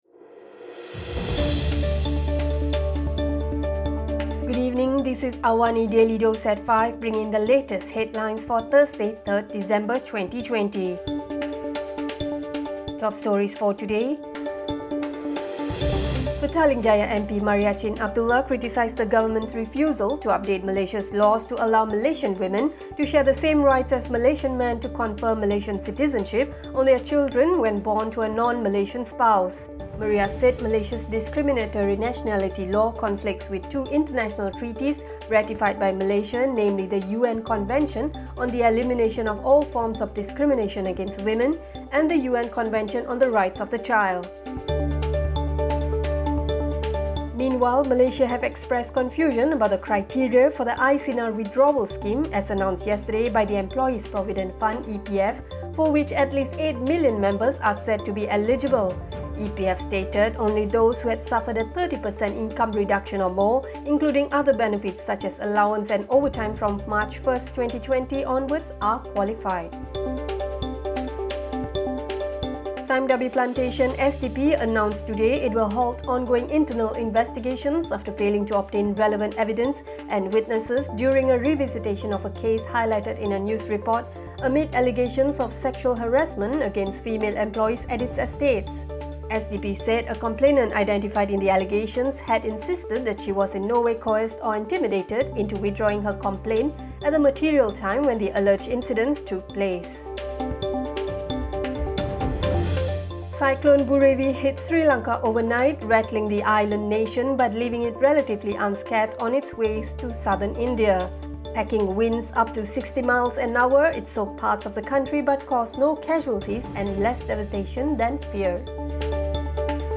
Also, the relocation of thousands of Rohingya refugees to Bhasan Char, a flood-prone Bay of Bengal island that emerged from the sea 20 years ago, poses grave concerns about independent human rights monitoring. Listen to the top stories of the day, reporting from Astro AWANI newsroom — all in 3 minutes.